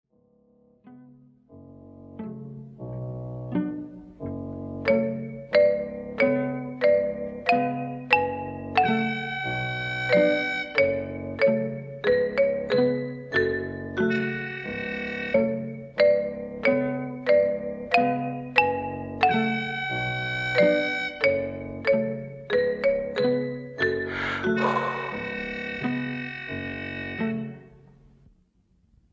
• Качество: 192, Stereo
грустные
без слов
тревожные
ксилофон
из мультфильмов